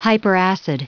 Prononciation du mot hyperacid en anglais (fichier audio)